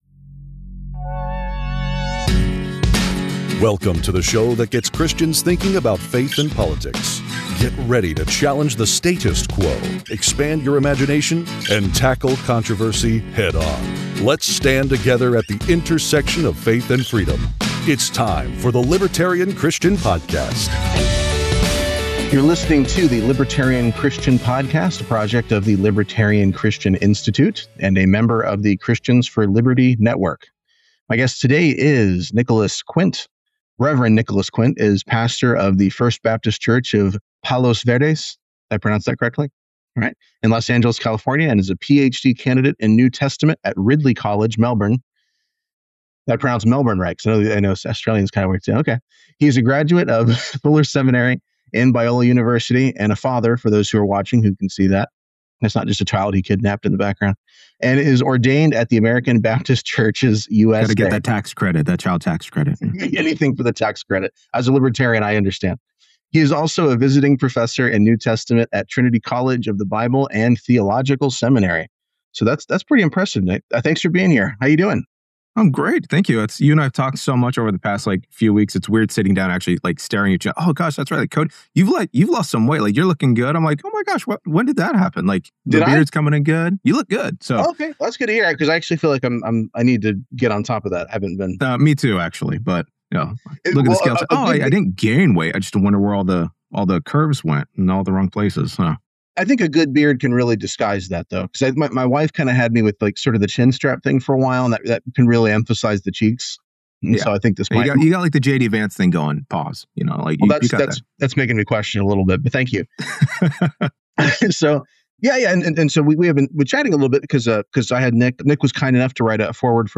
In a more theology-heavy discussion